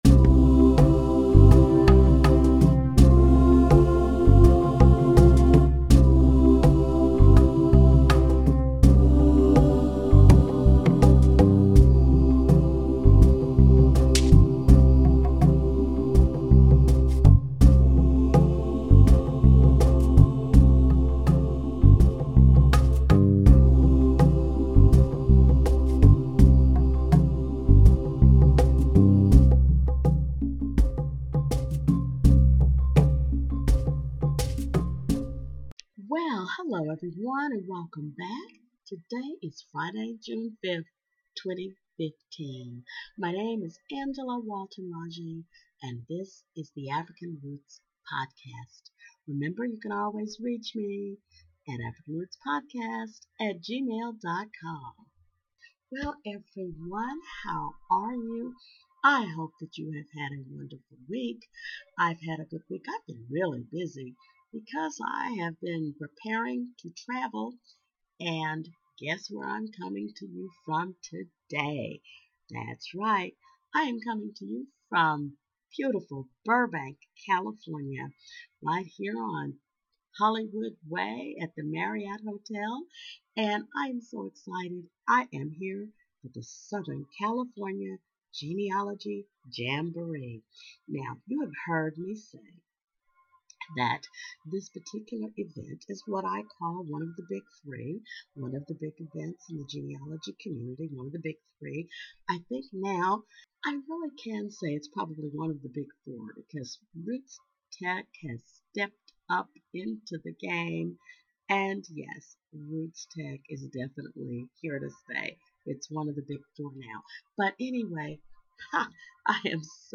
Well I am delighted to say that I am coming to you from Burbank California. I am here on the west coast to participate in the African American track at the Southern California Genealogy Jamboree!